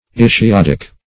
Search Result for " ischiadic" : The Collaborative International Dictionary of English v.0.48: Ischiadic \Is`chi*ad"ic\ ([i^]s`k[i^]*[a^]d"[i^]k), a. [L. ischiadicus, Gr.
ischiadic.mp3